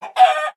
sounds / mob / chicken / hurt1.ogg